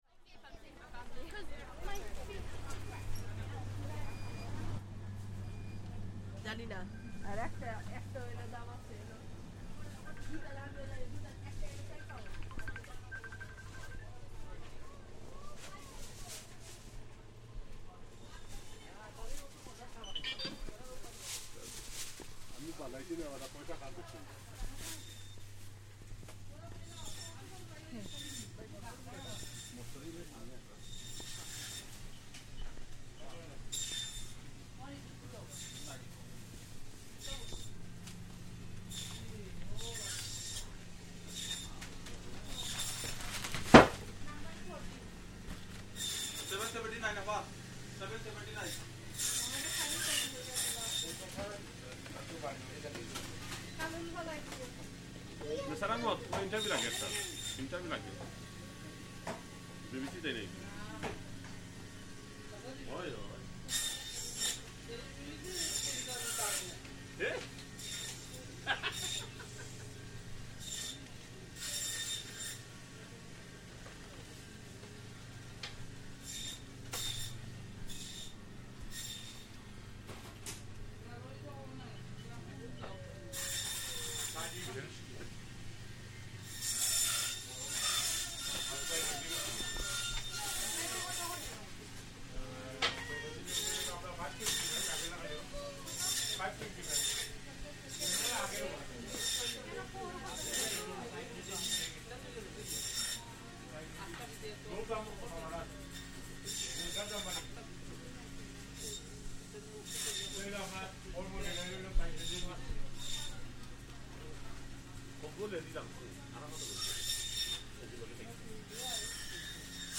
Sawing and chopping at the meat counter
Within that, Whitechapel is a major area for the Bangladeshi community, with many local specialist shops providing food from Bangladesh and beyond, such as Mas Bazaar. In this recording we can hear the butchers at the back of the shop sawing and chopping meat for preparation in their extensive fresh meat counter and enormous freezer stock.